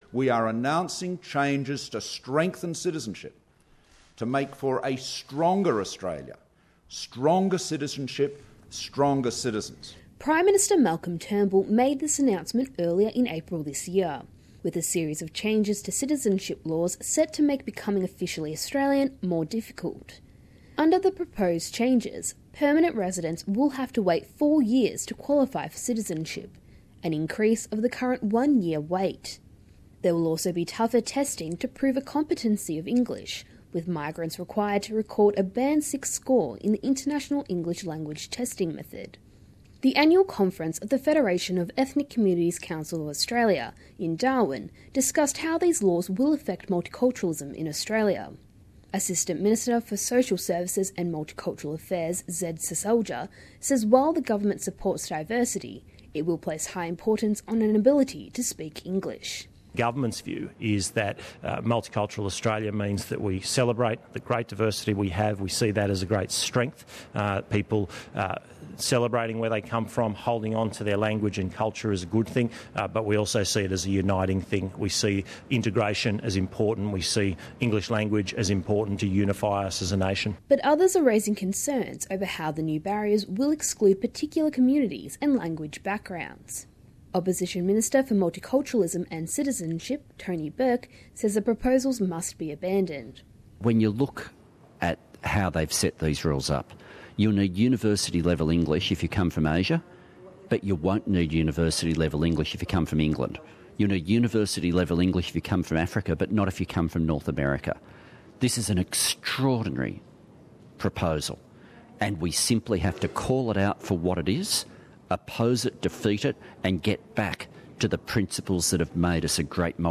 Labor spokesman for multiculturalism and citizenship Tony Burke at the FECCA conference Source: SBS